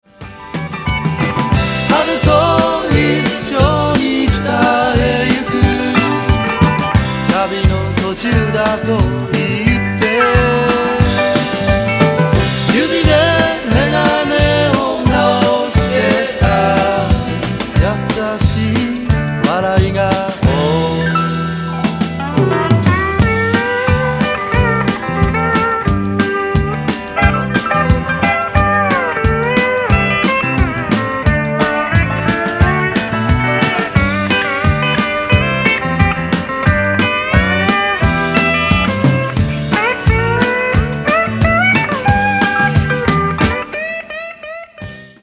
ジャンル：ロック